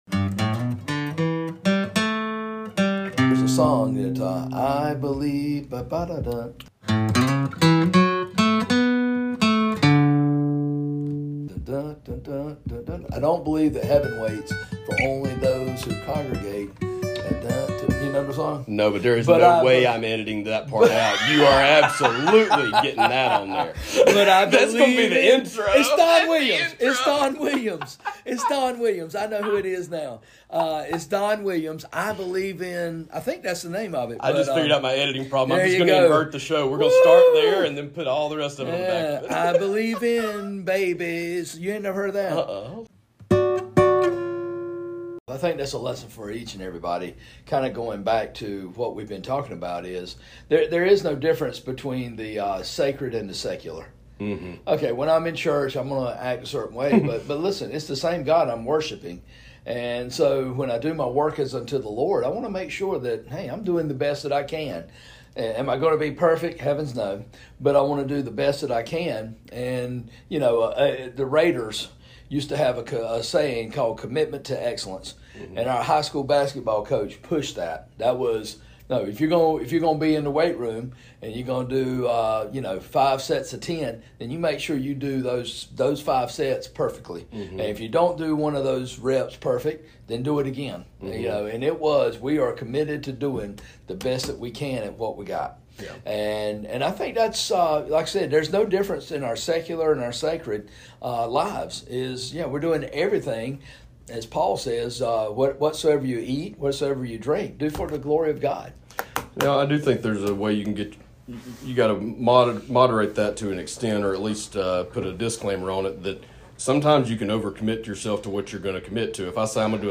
But 70 minutes or so of audio was neatly arranged, with all the cool stuff bunched together, separated with nice long meaningless ADD breaks that were easy to spot and eliminate.